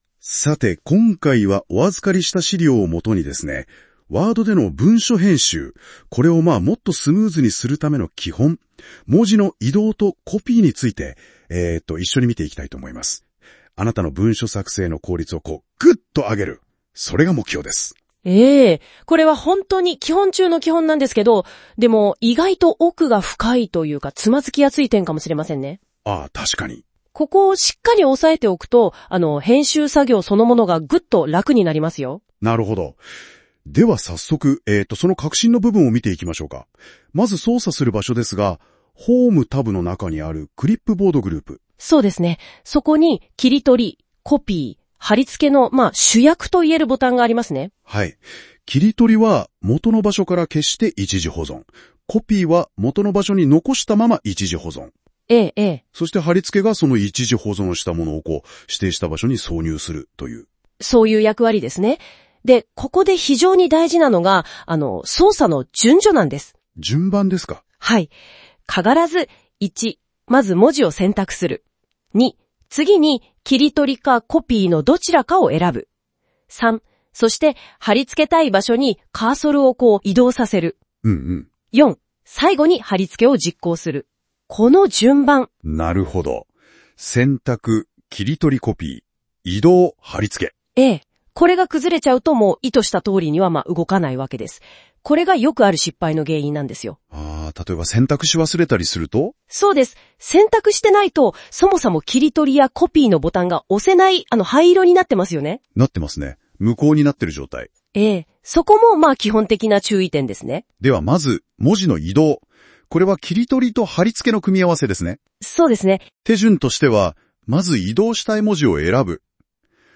生成AIでWordの音声講座創ってみた！！